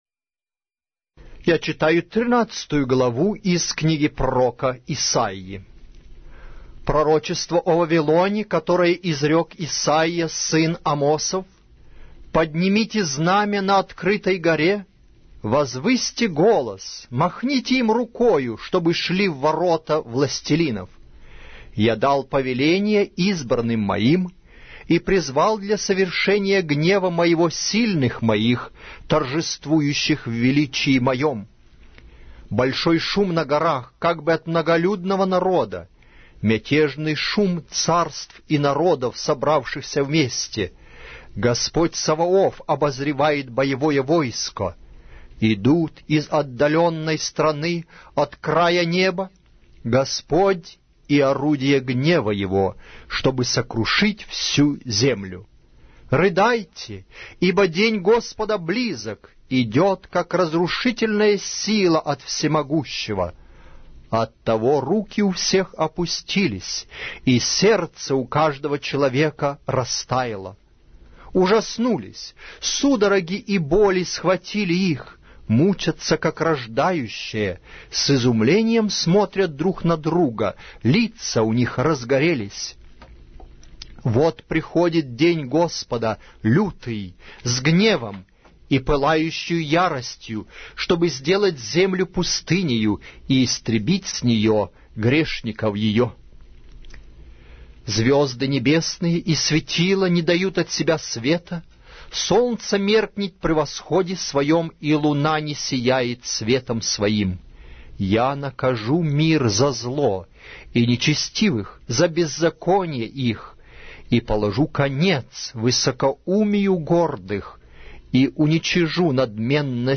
Аудиокнига: Пророк Исаия